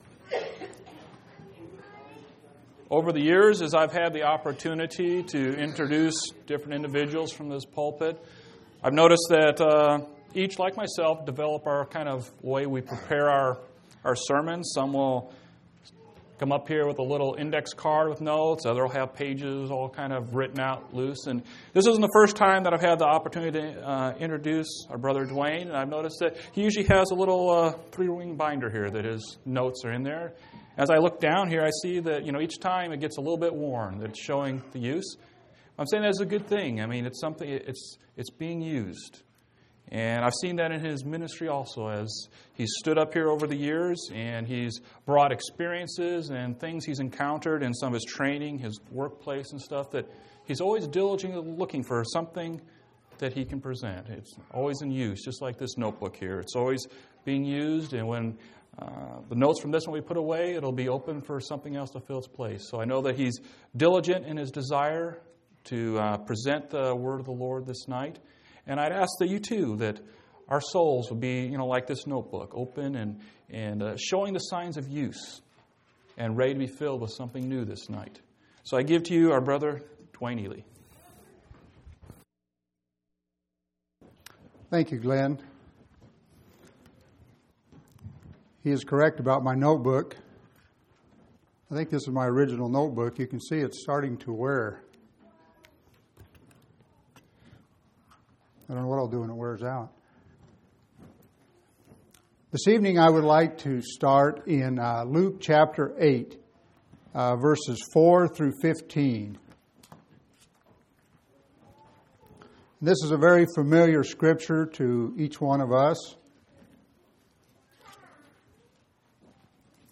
4/10/2005 Location: Phoenix Local Event
audio-sermons